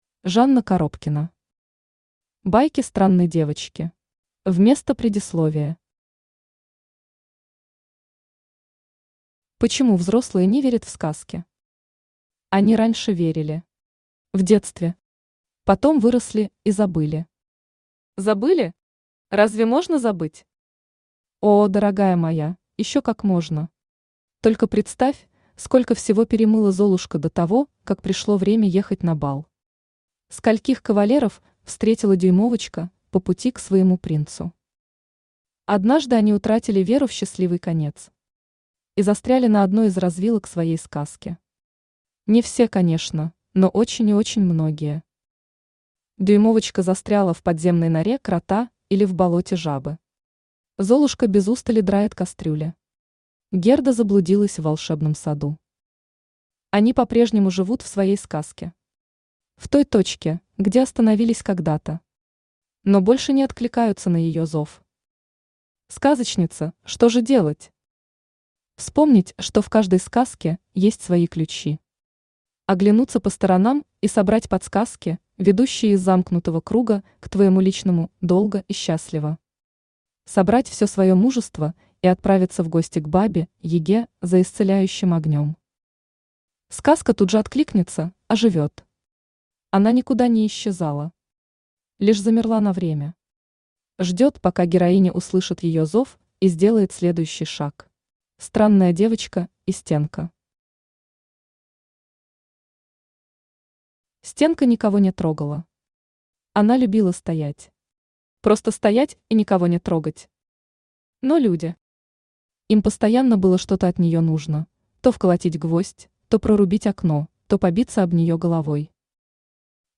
Аудиокнига Байки Странной девочки | Библиотека аудиокниг
Aудиокнига Байки Странной девочки Автор Жанна Коробкина Читает аудиокнигу Авточтец ЛитРес.